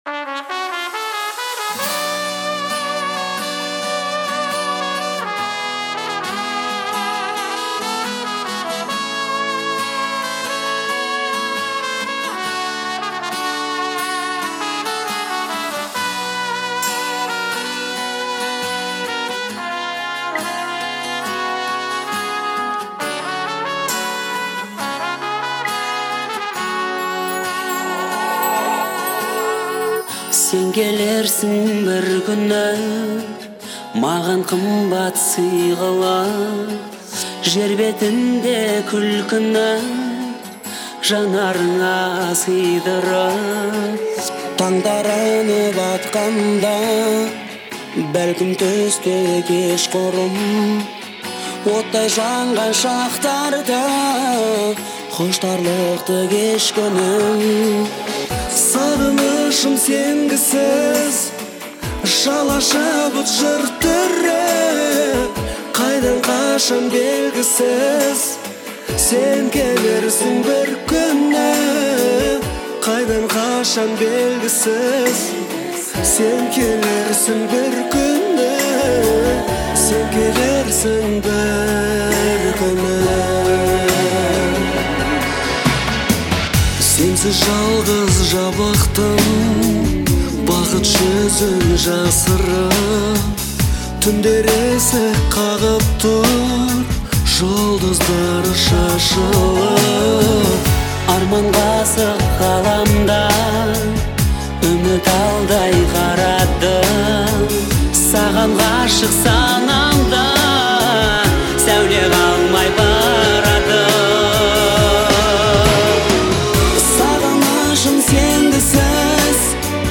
трогательная и мелодичная песня